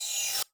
CYCdh_Kurz07-RevHat.wav